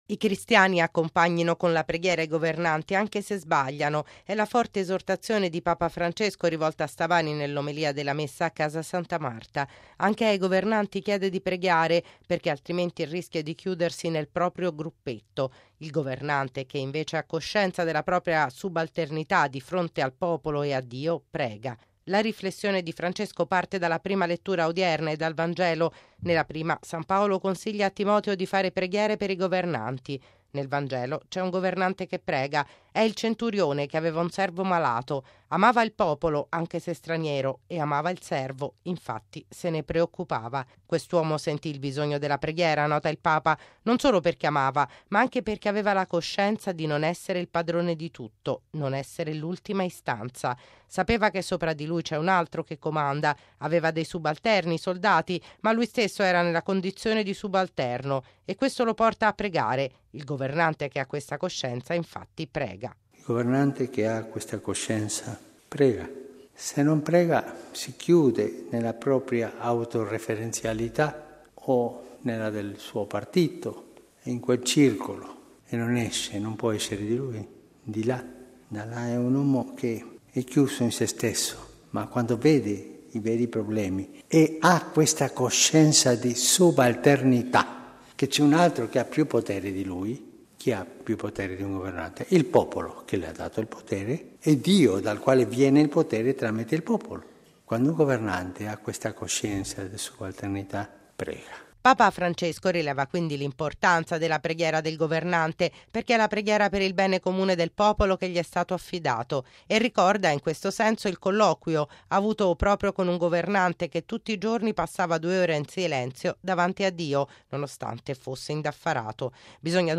I cristiani accompagnino con la preghiera i governanti, anche se sbagliano. E’ la forte esortazione di Papa Francesco rivolta stamani nell’omelia della Messa a Casa Santa Marta.